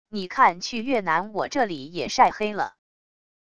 你看去越南我这里也晒黑了wav音频生成系统WAV Audio Player